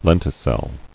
[len·ti·cel]